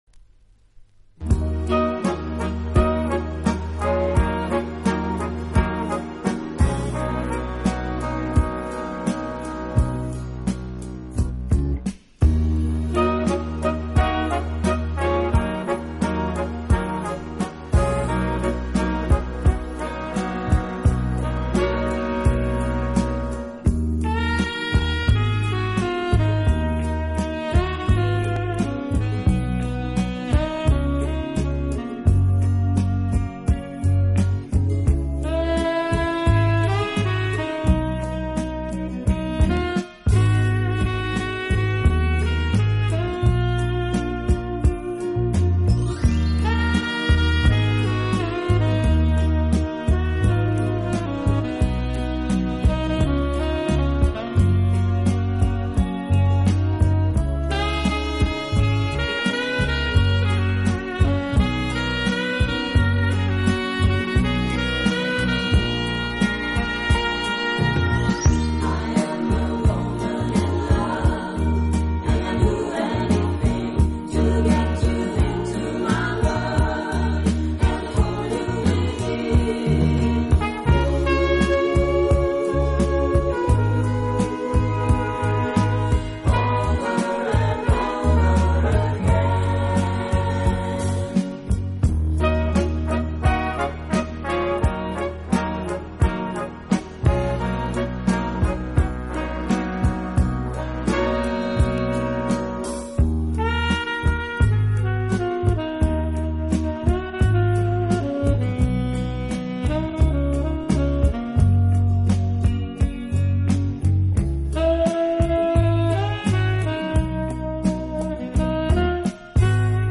【轻音爵士】
【爵士轻音乐】